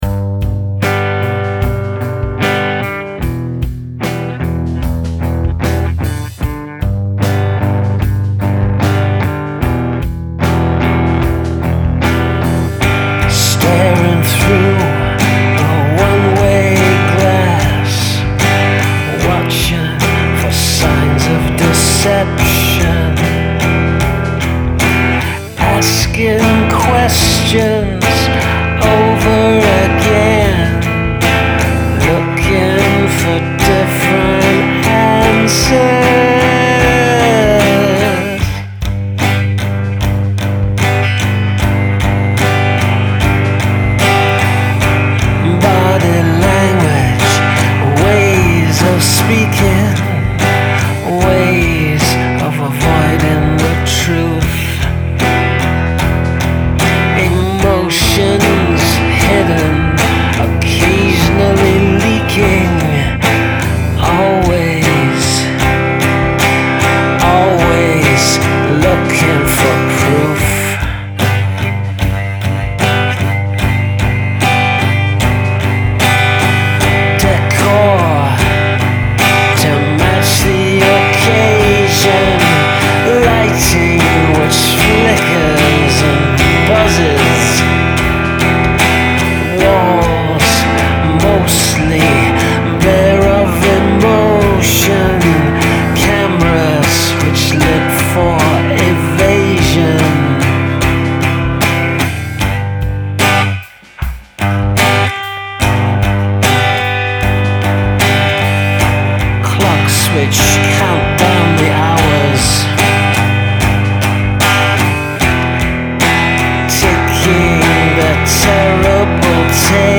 Oh missus, it's a darker one...
Love the loose hand dark sound of this one.